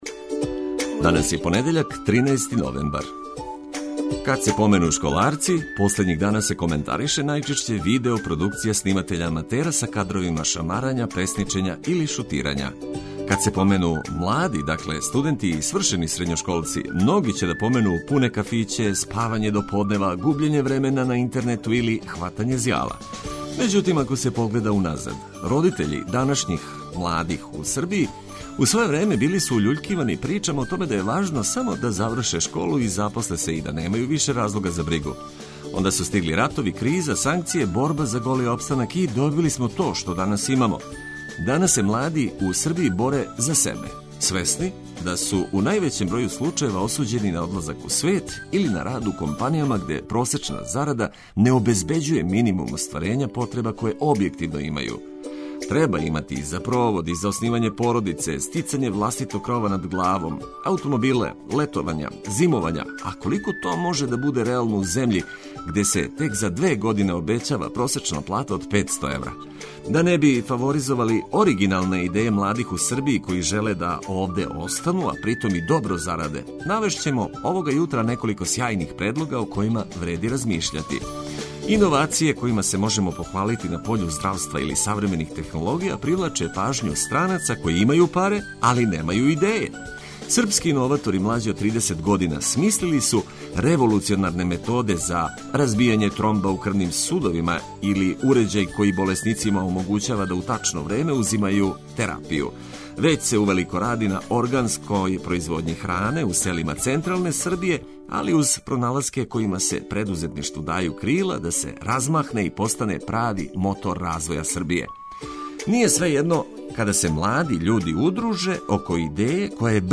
Припремили смо добро расположење, прикладну музику за разбуђивање и наравно, корисне информације за почетак новог дана.